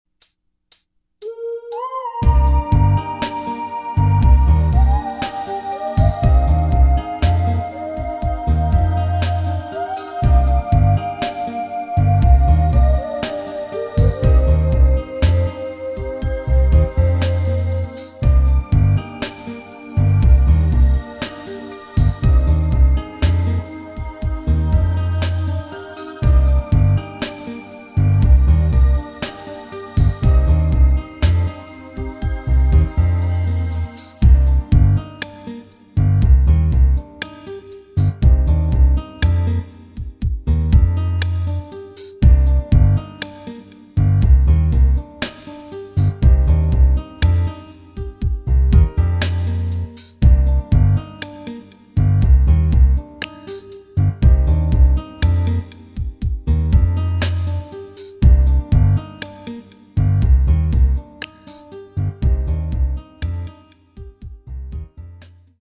Key of B flat